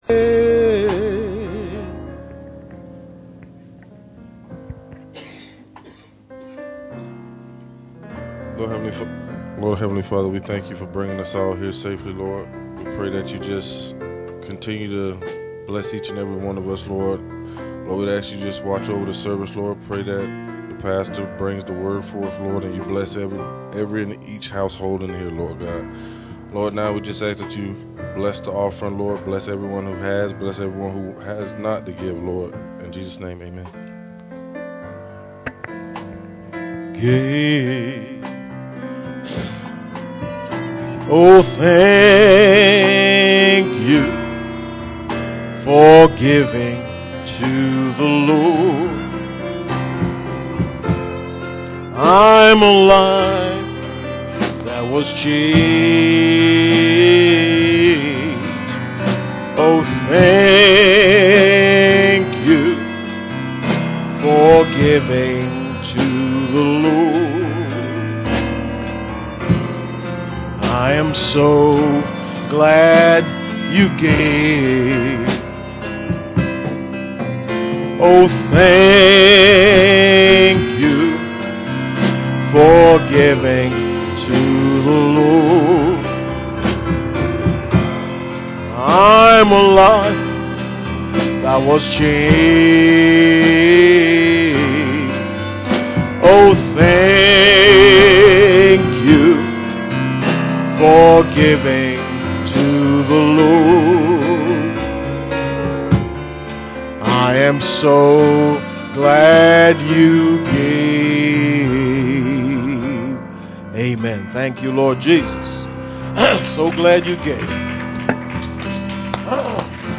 Pastor Passage: Ruth 3:3-12 Service Type: Sunday Morning %todo_render% « The Right Mental Attitude Ephesians Chapter 5